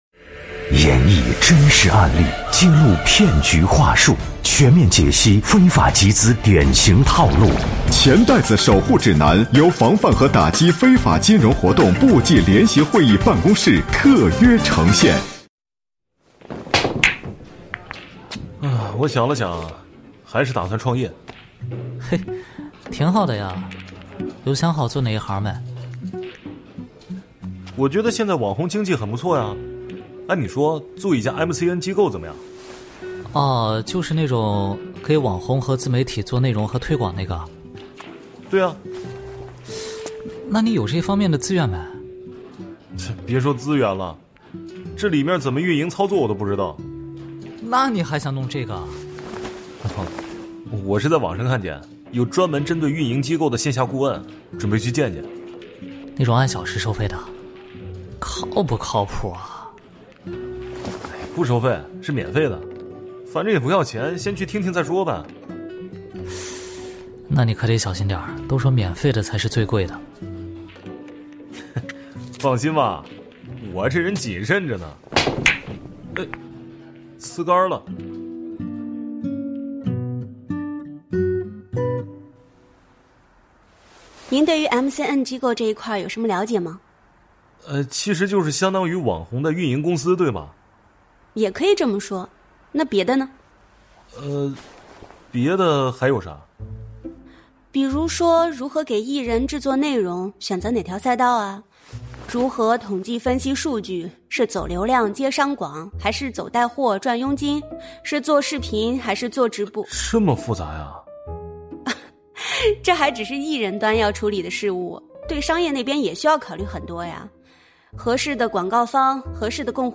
《钱袋子守护指南》栏目丨第十九集 网红经济的“机会” 来源：防范和打击非法金融活动部际联席会议办公室和云听联合呈现 时间：2025-02-10 19:40 微信 微博 QQ空间 《钱袋子守护指南》是经济之声联合防范和打击非法金融活动部际联席会议办公室特别策划推出的一档防范非法集资科普栏目。选取真实案例，透过典型情节演示非法集资对个人和社会带来的危害，更生动地传递“反非”的理念，增强社会的风险意识和预防能力。